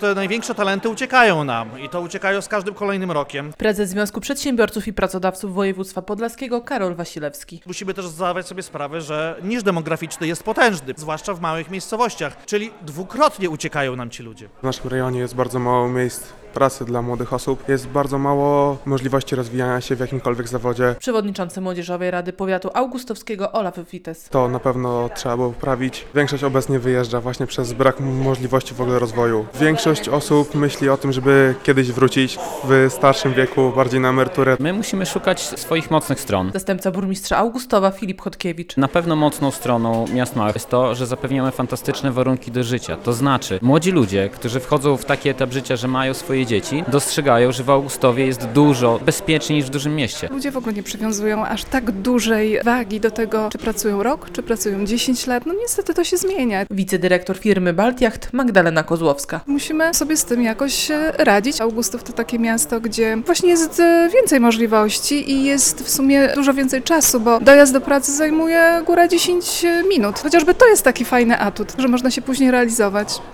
Między innymi o tym, w jaki sposób zatrzymać młodych ludzi w małych miejscowościach rozmawiali w piątek (24.10) w Augustowie samorządowcy, naukowcy oraz przedsiębiorcy, podczas Kongresu Rozwoju Regionu - IMPULS.